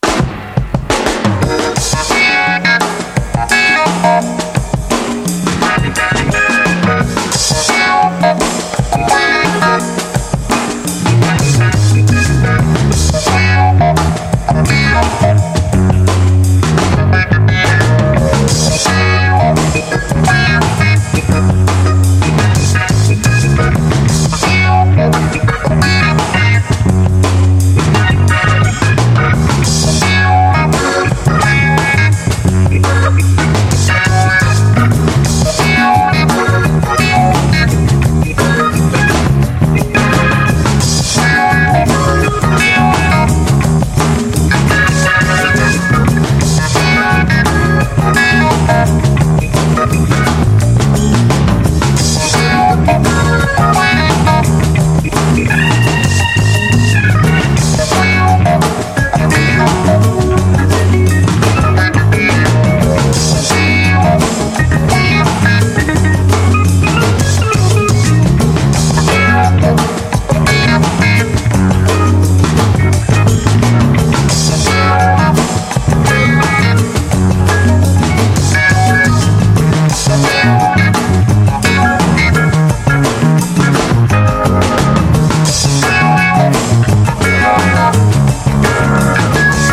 現代的な切れ味とオーセンティックなセンスが交錯する強力インスト満載！